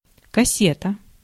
Ääntäminen
Synonyymit coffret Ääntäminen France: IPA: /ka.sɛt/ Haettu sana löytyi näillä lähdekielillä: ranska Käännös Ääninäyte Substantiivit 1. ящичек (jaštšitšek) Muut/tuntemattomat 2. кассета {f} (kasseta) Suku: f .